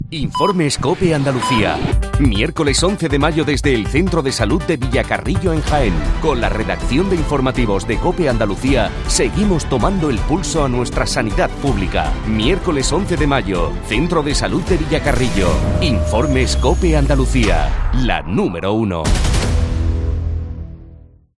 Este miércoles, Chequeamos a la Sanidad Pública desde el Centro de Salud de Villacarrillo (Jaén)